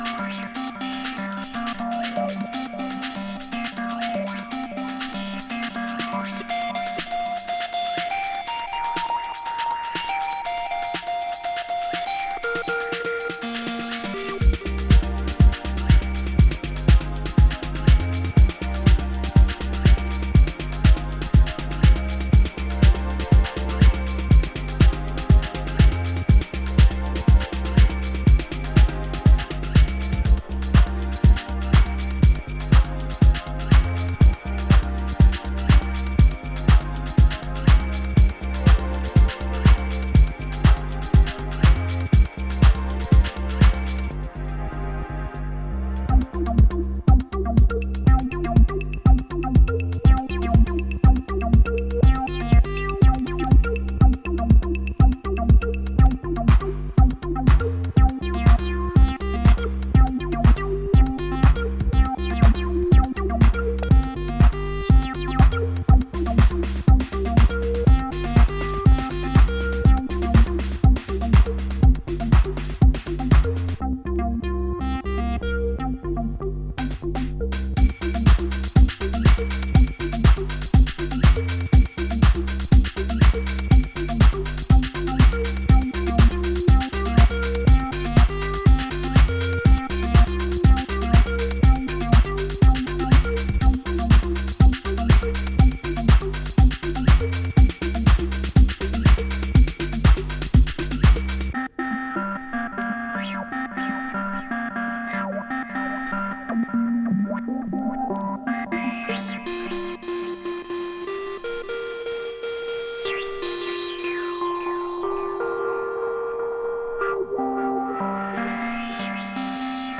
今回もシルキーでメロディアスなシンセワークやアナログマシンのダスティなグルーヴを駆使したディープ・ハウスを展開。